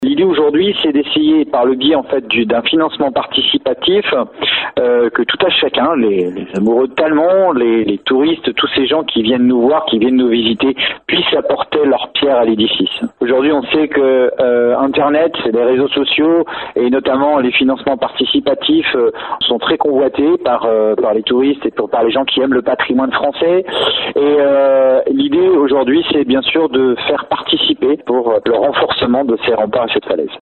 L’idée est donc de faire appel au financement participatif. On écoute le maire Stéphane Loth :